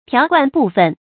条贯部分 tiáo guàn bù fēn
条贯部分发音